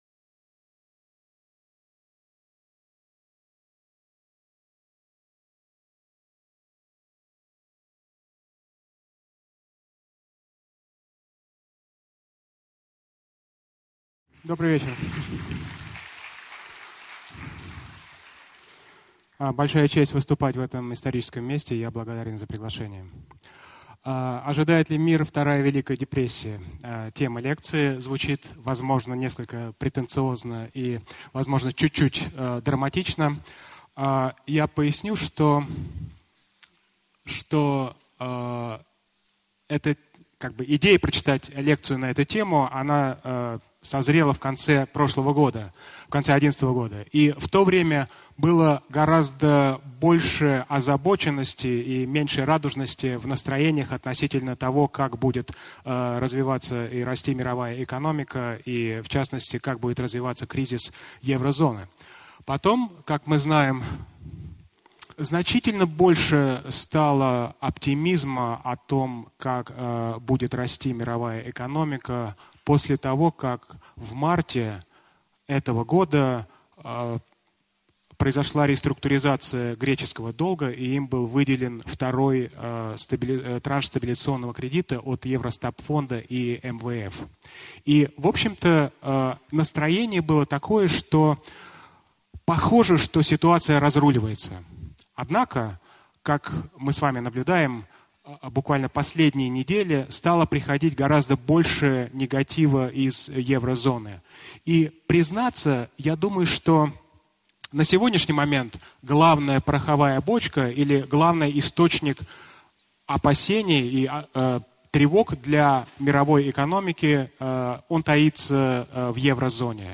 Аудиокнига Лекция №01